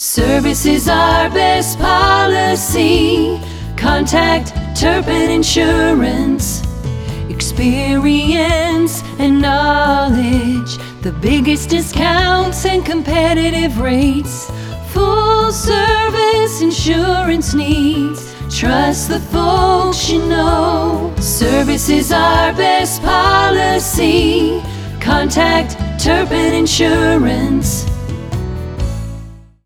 National quality jingles at competitive prices!